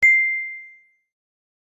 Ping.ogg